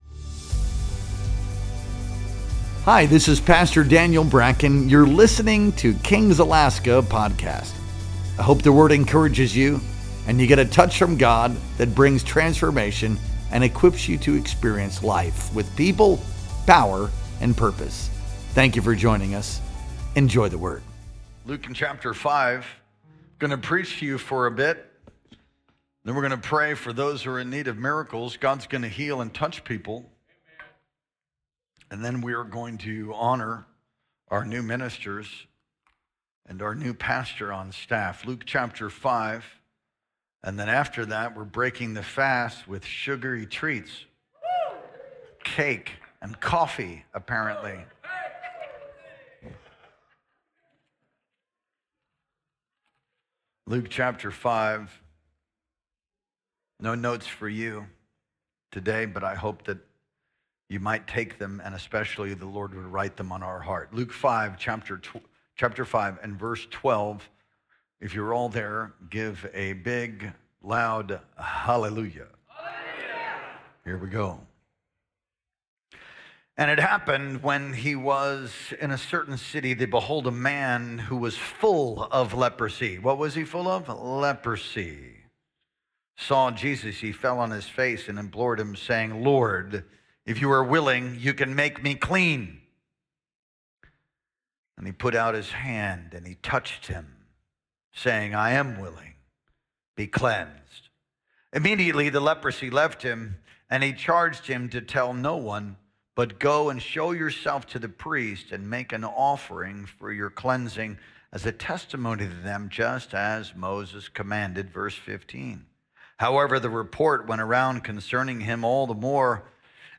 Our Sunday Night Worship Experience streamed live on January 26th, 2025.